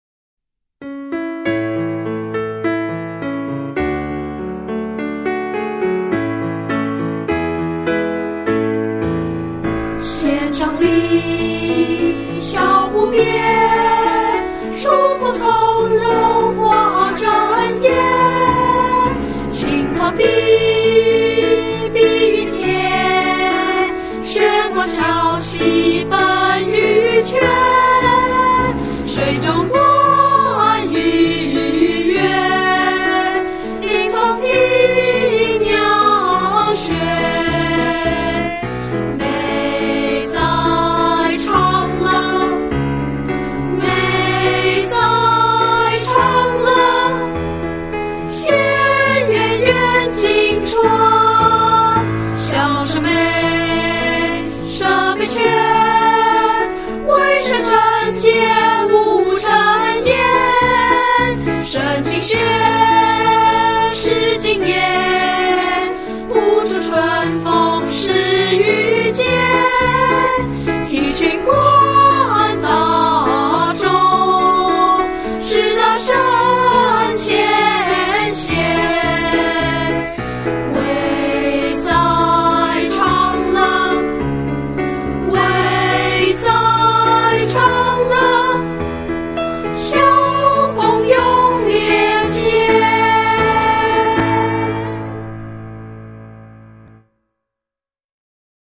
學校校歌